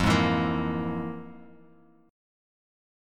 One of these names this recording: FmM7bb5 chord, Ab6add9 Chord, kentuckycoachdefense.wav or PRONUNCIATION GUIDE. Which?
FmM7bb5 chord